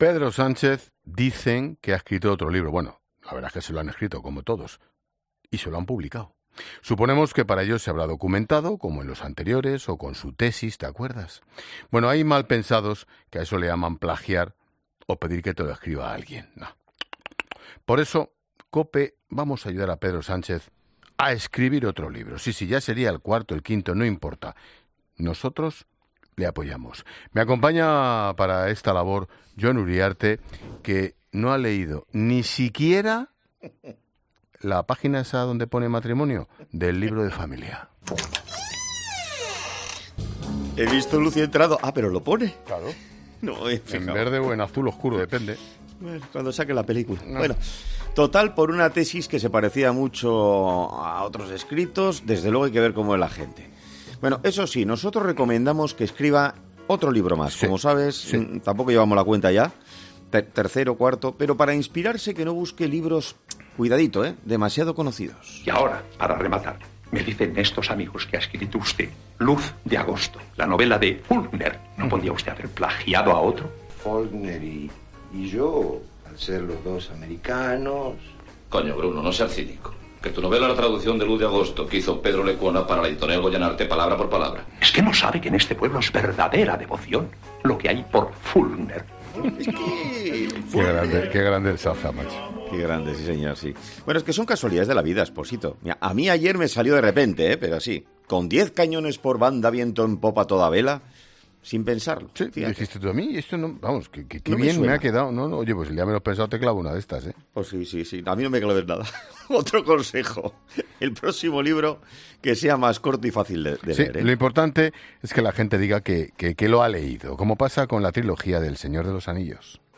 El director de La Linterna, Ángel Expósito, y el comunicador Jon Uriarte han recomendado al presidente del Gobierno, Pedro Sánchez, qué hacer para cuando publique un nuevo libro